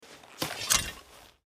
skyrim_mace_draw1.mp3